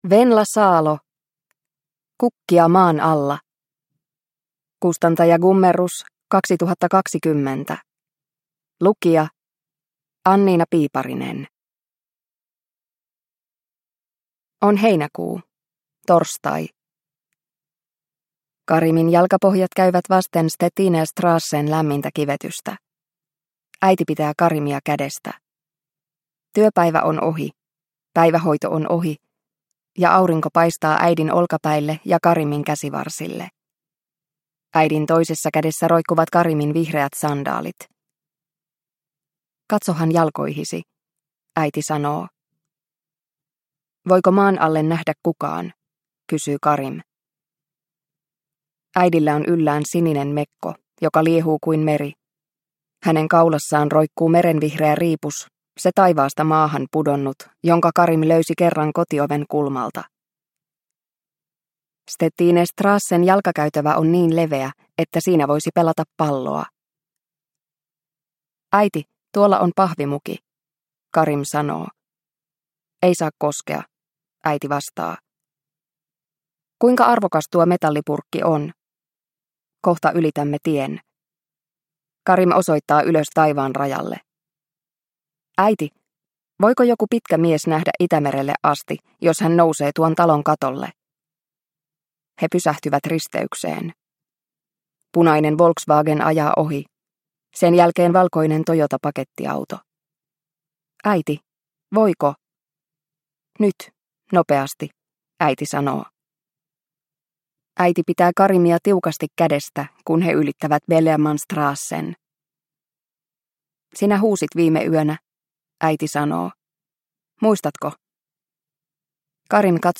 Kukkia maan alla – Ljudbok – Laddas ner